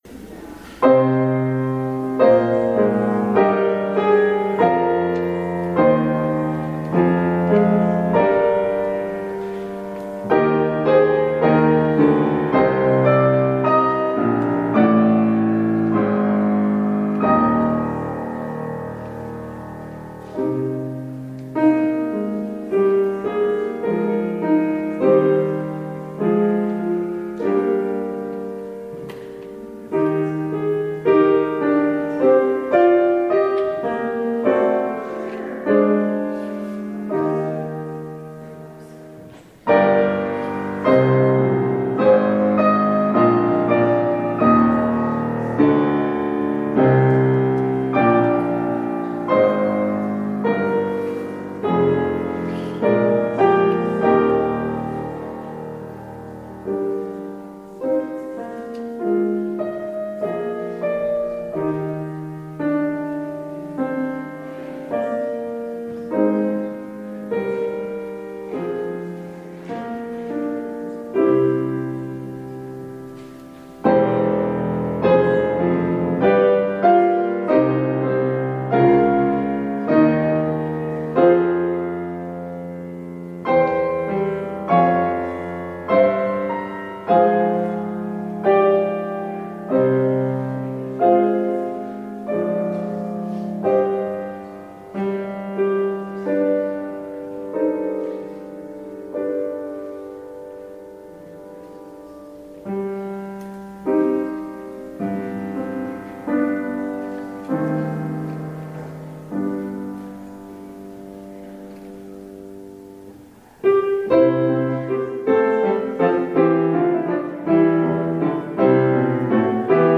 Audio recording of the 10am service